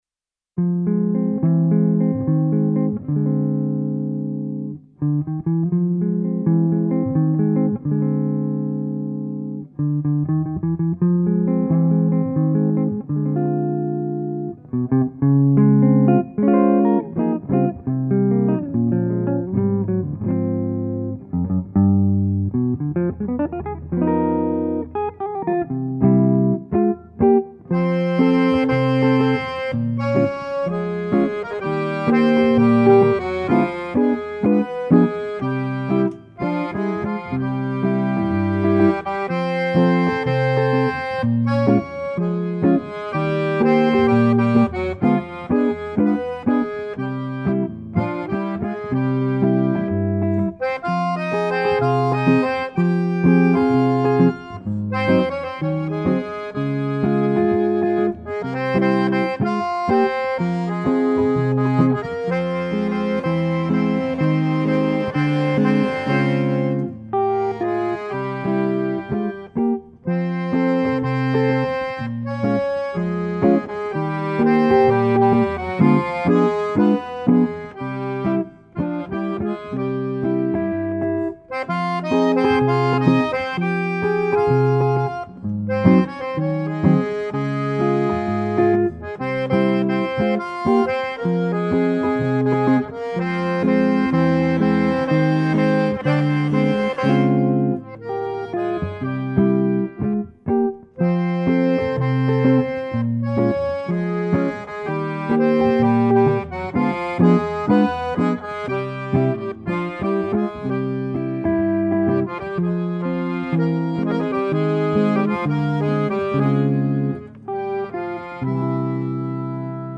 accordion
guitar